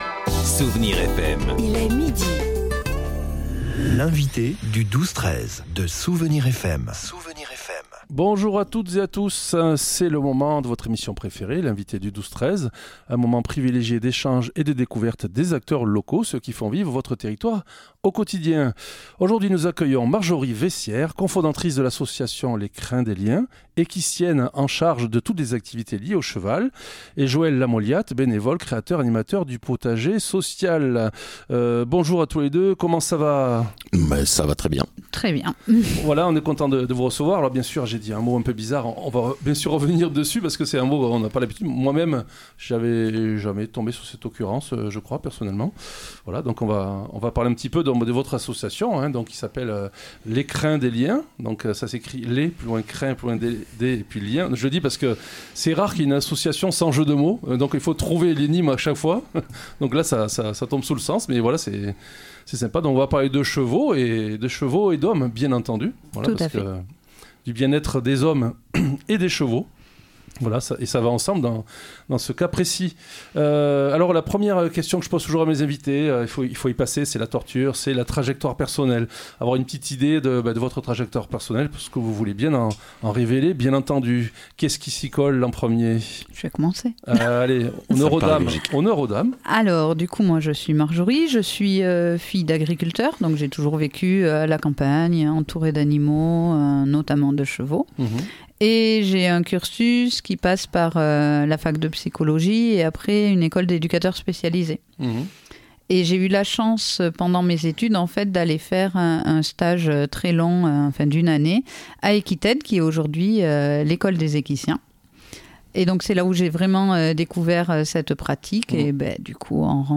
L'invité(e) du 12-13 de SOUSTONS recevais aujourd'hui l'association Les crins des liens.